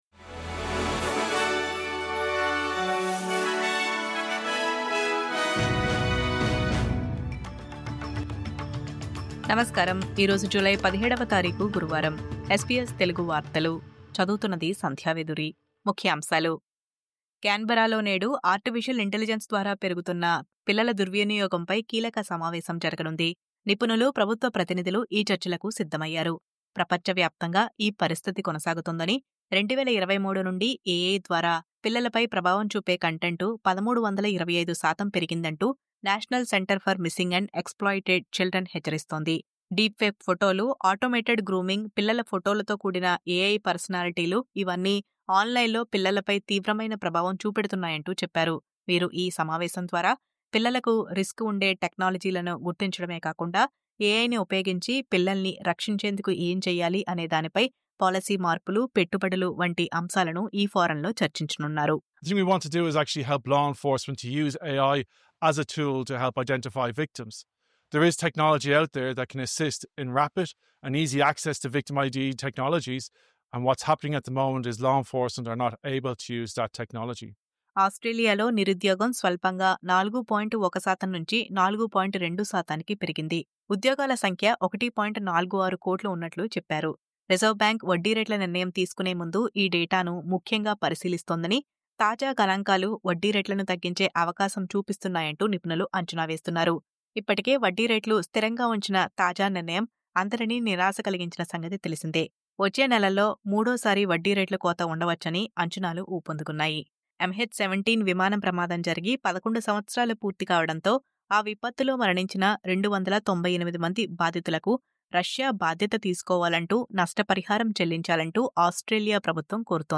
News Update: చిన్నారులపై AI ముప్పు…